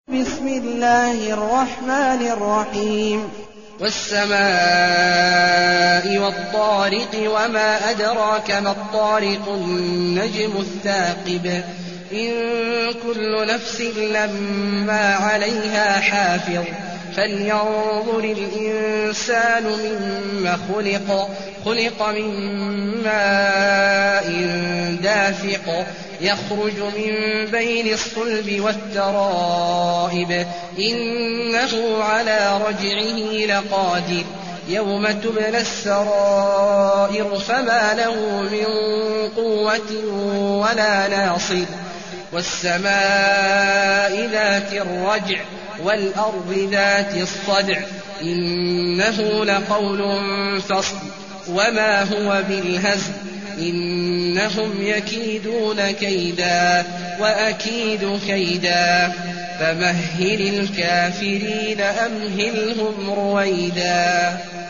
المكان: المسجد النبوي الشيخ: فضيلة الشيخ عبدالله الجهني فضيلة الشيخ عبدالله الجهني الطارق